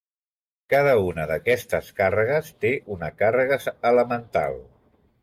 Pronounced as (IPA) [ˈte]